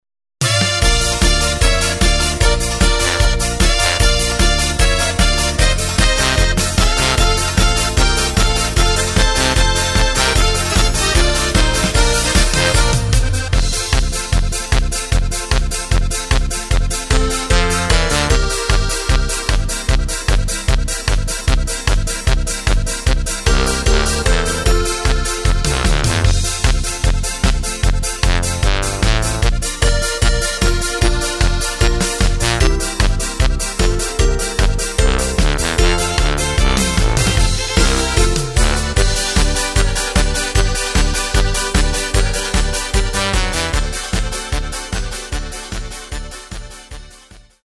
Takt:          2/4
Tempo:         151.00
Tonart:            Bb
Flotte Polka aus dem Jahr 2013!
Playback mp3 mit Lyrics